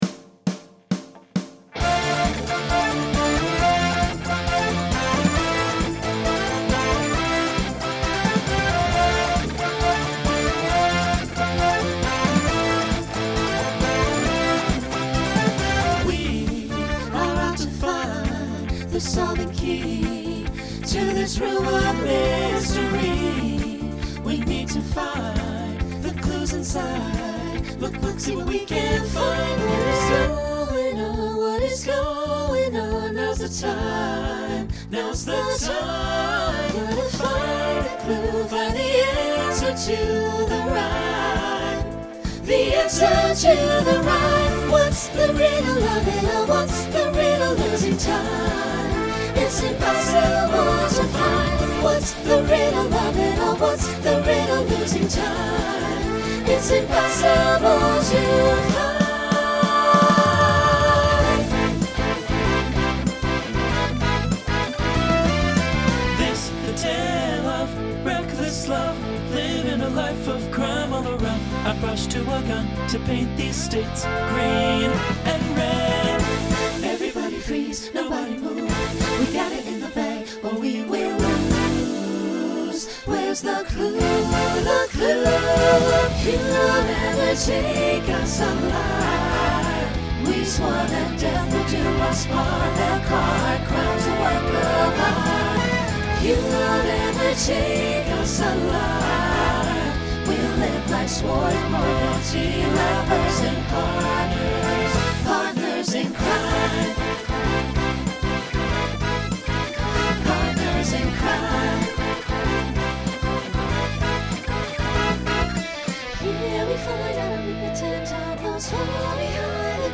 2010s Instrumental combo Genre Pop/Dance , Rock
Story/Theme Voicing SATB